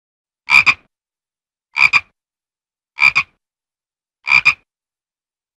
Frog Sound
animal